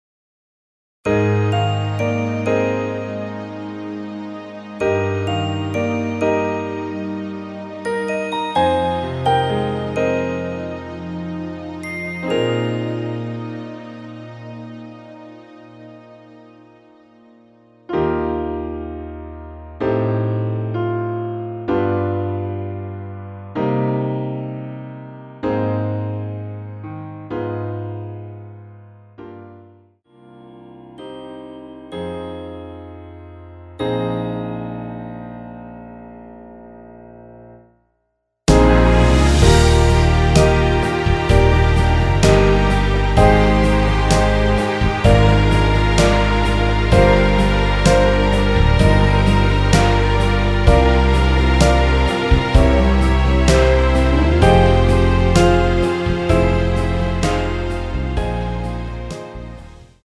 남성분이 부르실수 있는 키로 제작 하였습니다.(미리듣기 참조)
Eb
앞부분30초, 뒷부분30초씩 편집해서 올려 드리고 있습니다.
중간에 음이 끈어지고 다시 나오는 이유는